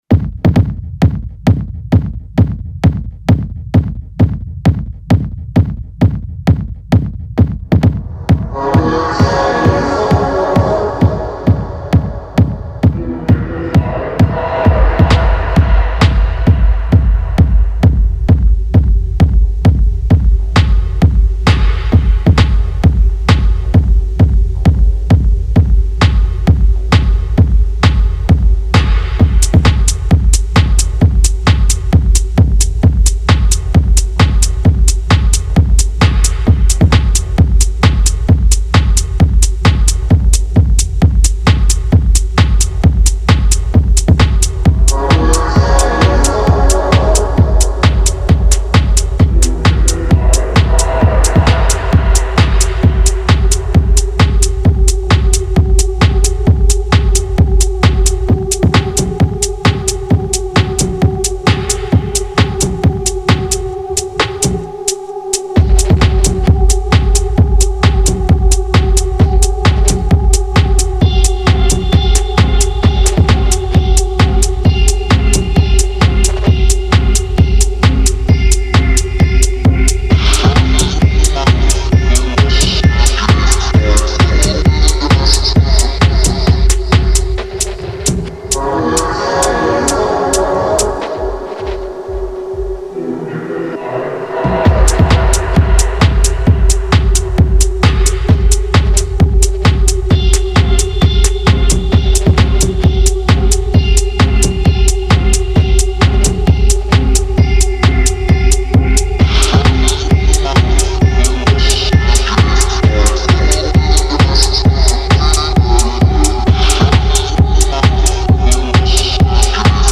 exuding a hypnotic and dreamy atmosphere